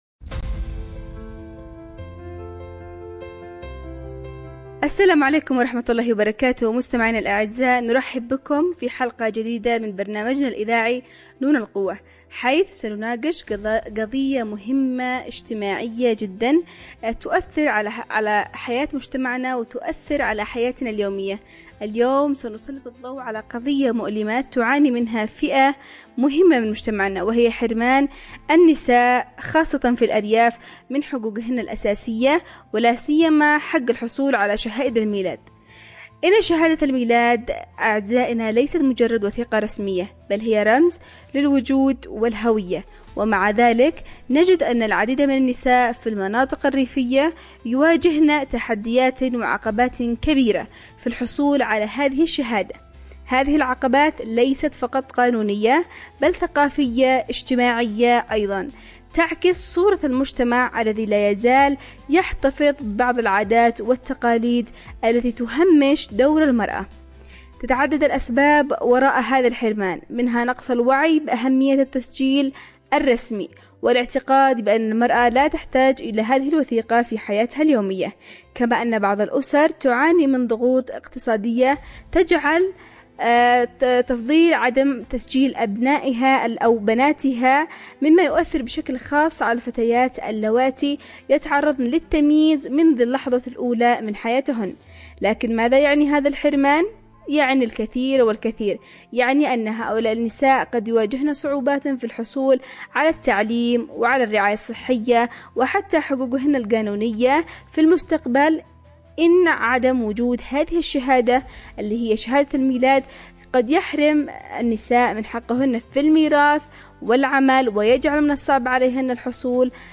نناقش كيف أن غياب هذه الوثيقة الأساسية يحرم الكثير من الفتيات من حقوقهن التعليمية والصحية، ويؤثر على مستقبلهن القانوني والاجتماعي. 📅 الموعد: الثلاثاء ⏰ الساعة: 11:00 صباحًا 📻 عبر أثير إذاعة رمز ✨ حلقة تفتح النقاش حول التحديات التي تواجه الأسر الريفية في تسجيل المواليد، وكيف يمكن للمجتمع المدني والمؤسسات المعنية أن تتدخل لضمان هذا الحق الأساسي.